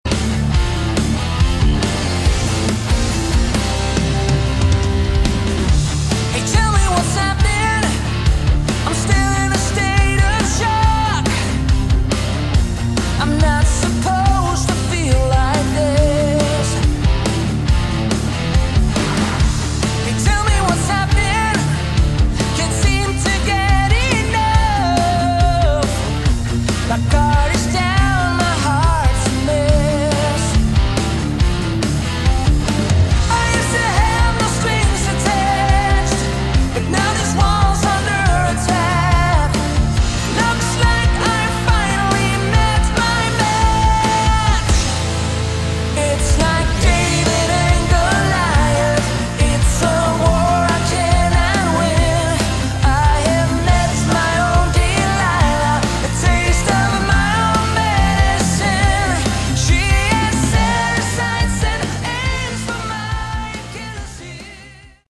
Category: AOR / Melodic Rock
vocals
guitar, bass, keyboards
drums, bass